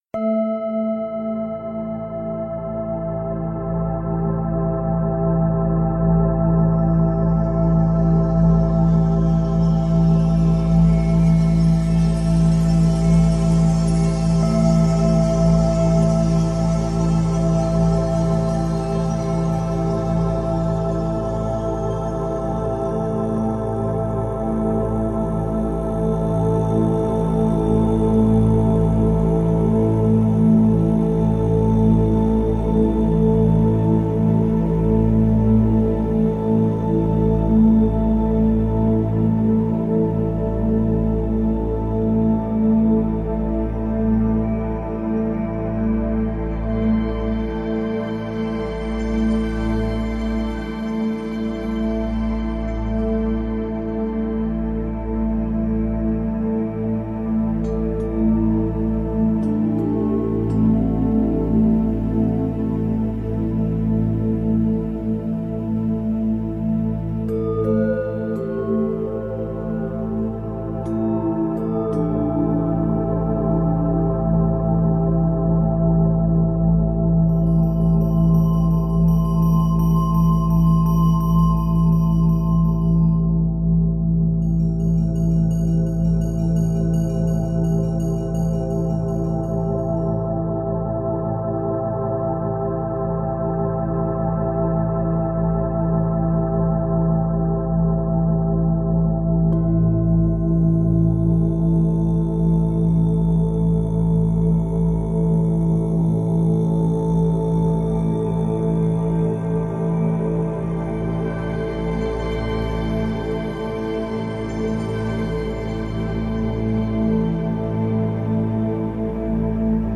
Ce chant vibratoire accroît la clairvoyance